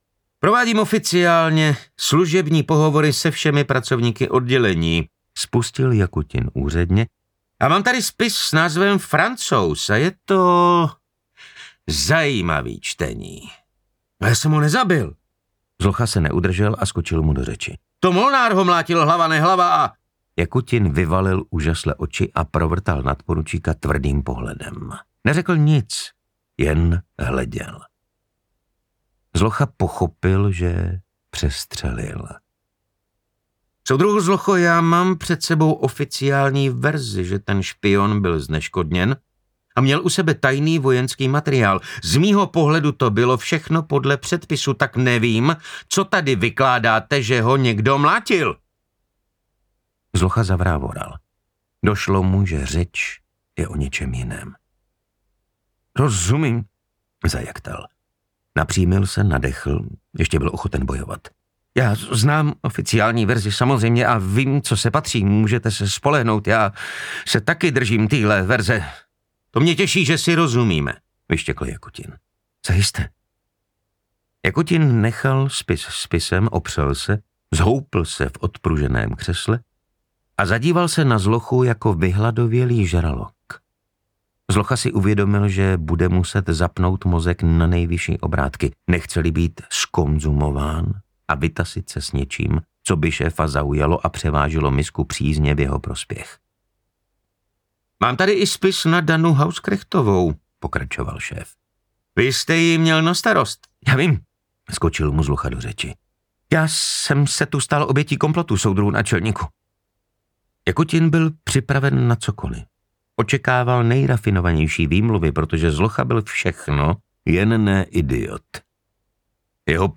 Básník audiokniha
Ukázka z knihy